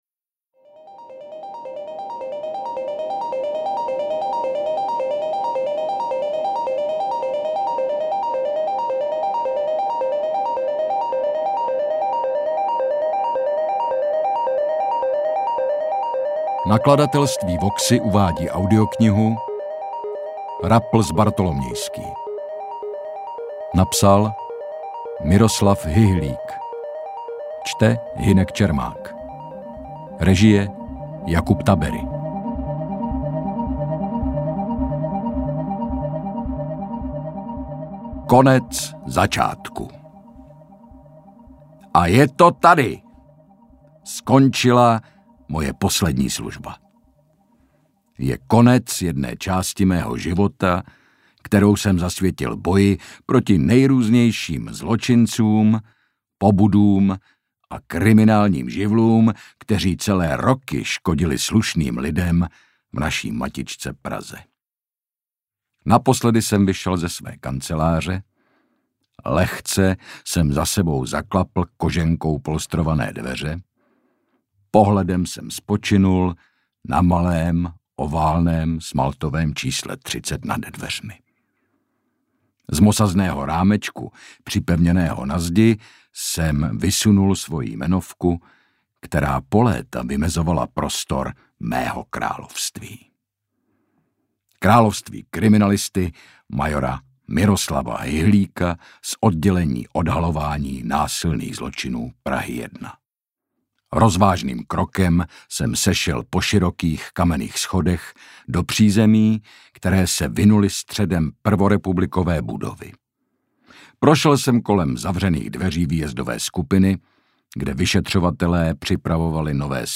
Interpret:  Hynek Čermák
AudioKniha ke stažení, 46 x mp3, délka 9 hod. 36 min., velikost 524,0 MB, česky